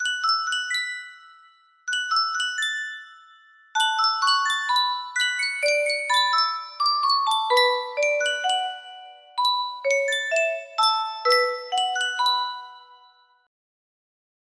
Yunsheng Music Box - 雨夜花音乐盒 4482 music box melody
Full range 60